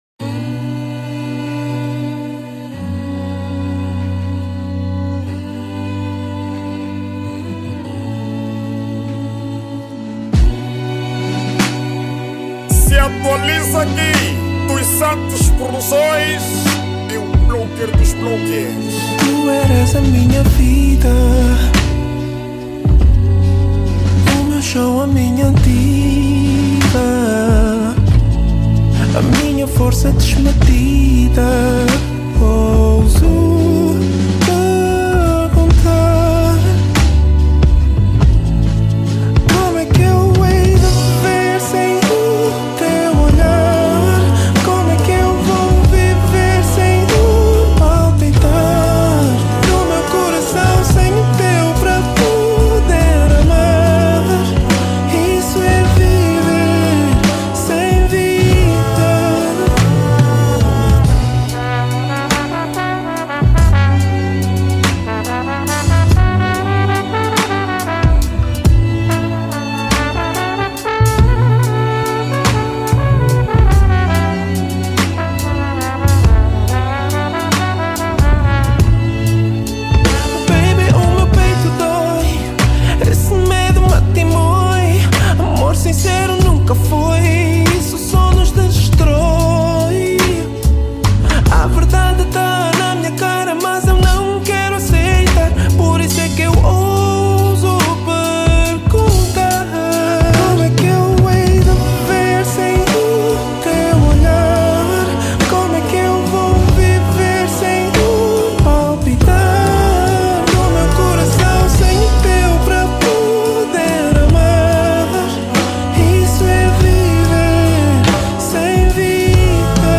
ESTILO DA MÚSICA:  R&B
MUSIC STYLE: R&B